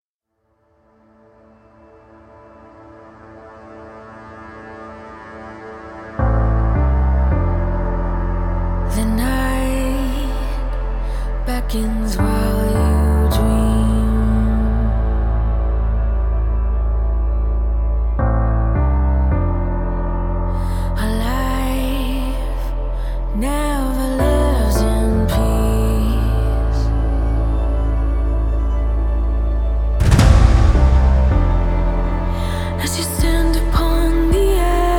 Жанр: Альтернатива / Музыка из фильмов / Саундтреки